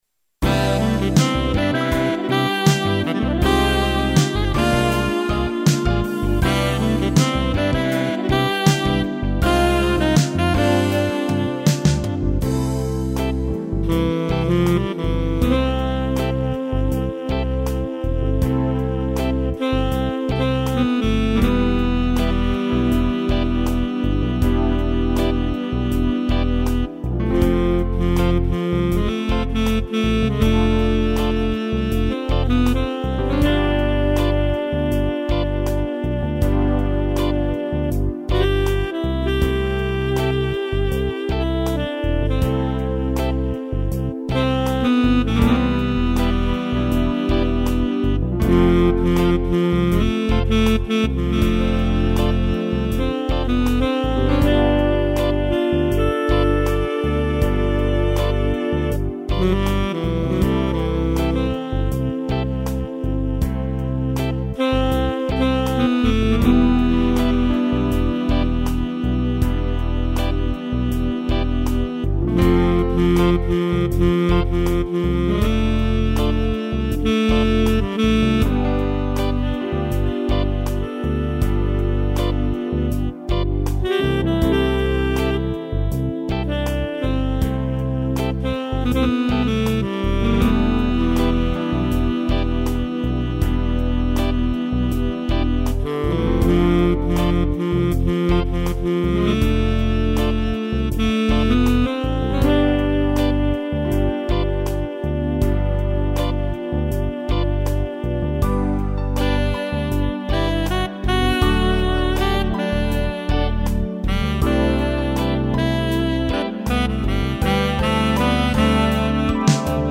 instrumental
arranjo e intepretação teclado